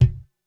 Kick 01.wav